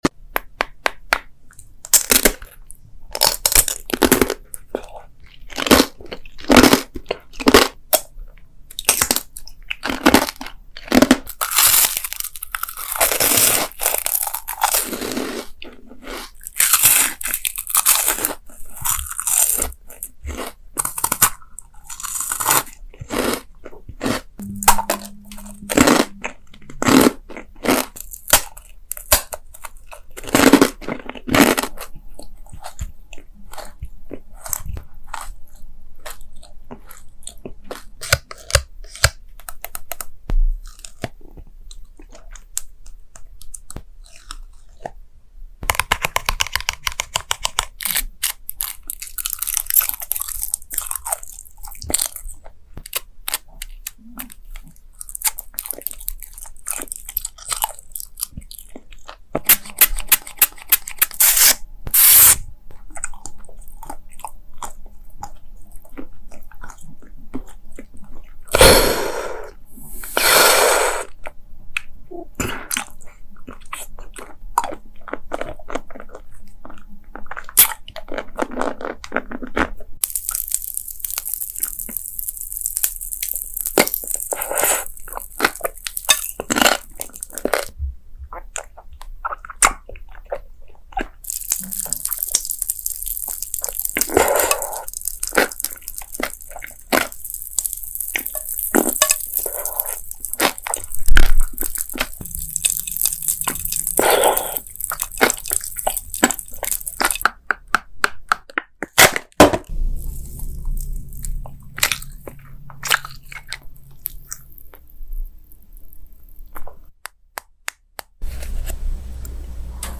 Crisp sound sound effects free download